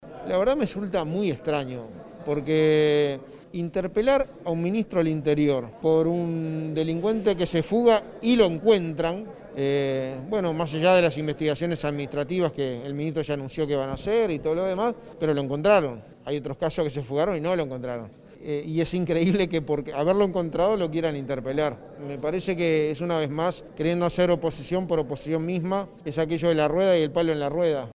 «Resulta muy extraño interpelar a un ministro del Interior por un delincuente que se fuga y lo encuentran, es increíble que por haberlo encontrado lo quieran interpelar», sostuvo el secretario de Presidencia, Álvaro Delgado, en rueda de prensa consignada por Canal 5.